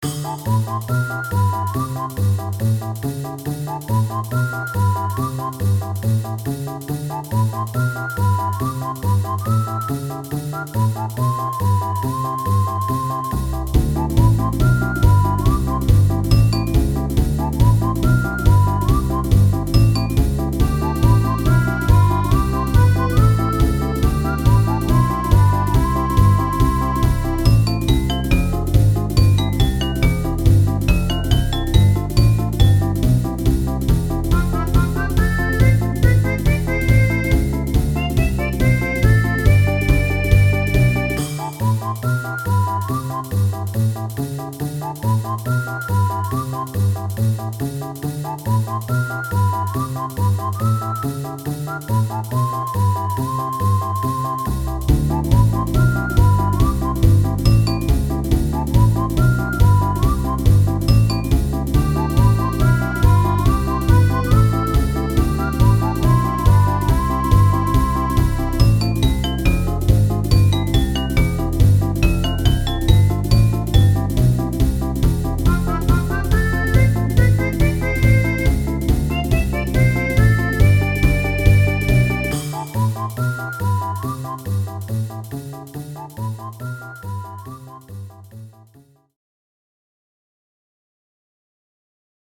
Light and Silly